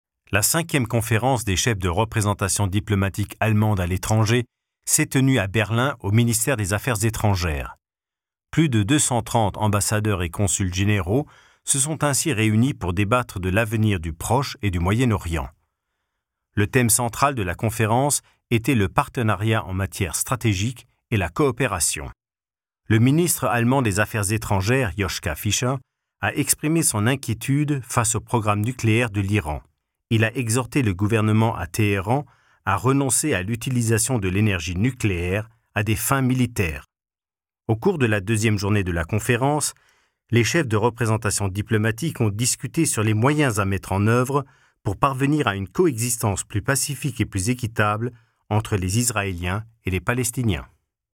Französischer Schauspieler und Sprecher, Muttersprache französisch, deutsch mit französischem Akzent
Sprechprobe: Werbung (Muttersprache):
voice over artist french